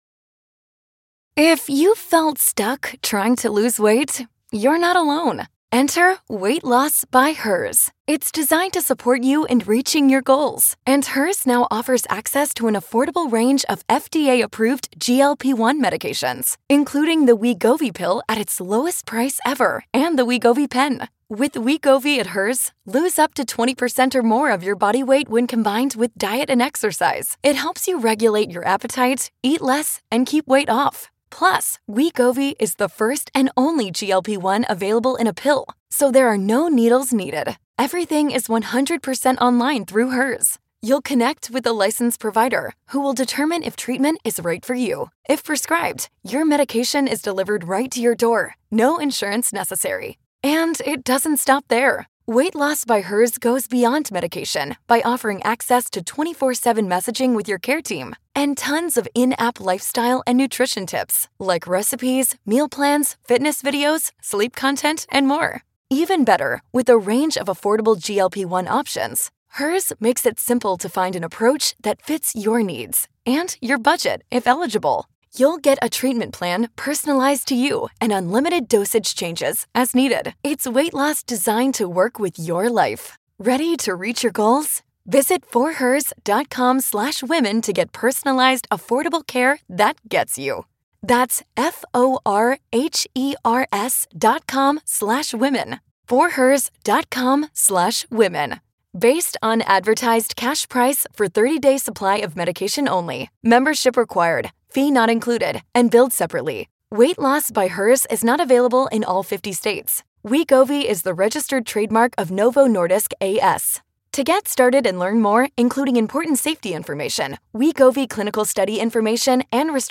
from spelling bees, impromptu trips, public speaking, and so much more, please enjoy this amazing discussion between two professionals right now!!